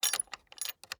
Doors Gates and Chests
Lock Unlock.ogg